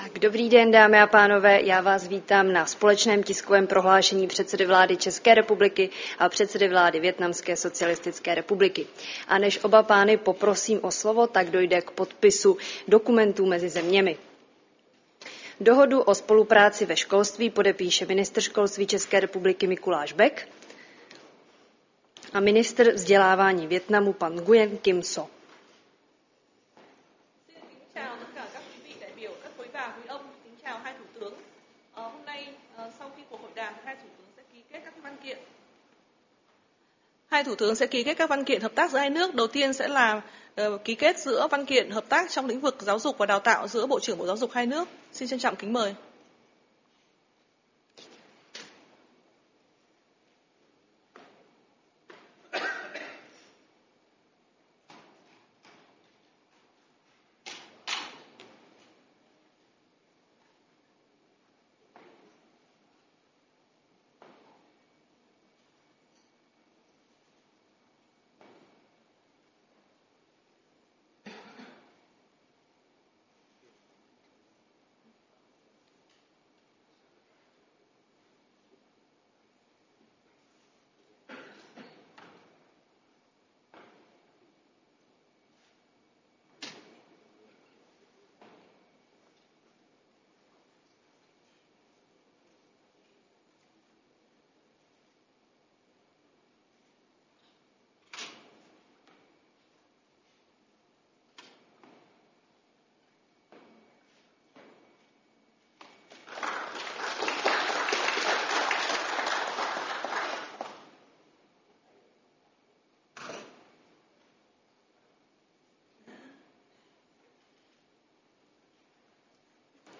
Společné tiskové prohlášení předsedy vlády ČR a předsedy vlády Vietnamské socialistické republiky